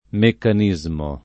meccanismo [ mekkan &@ mo ] s. m.